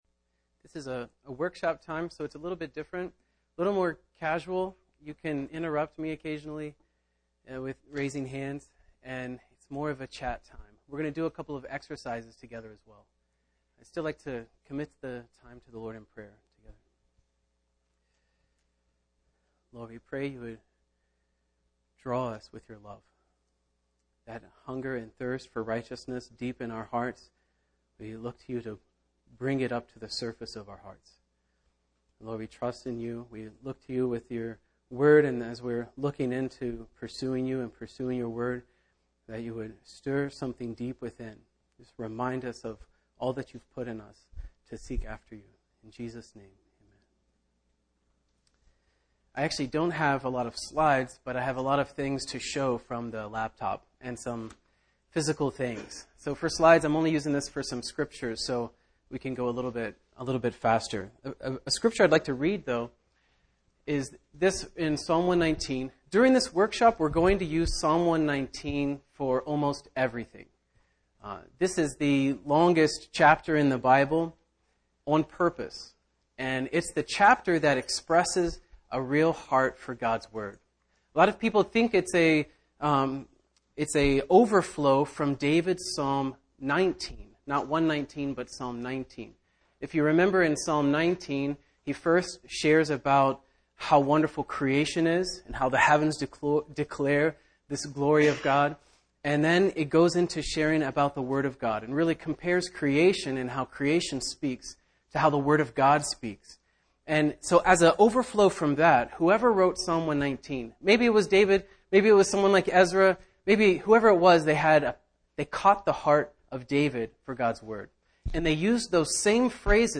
Winter Young Peoples Gathering 2015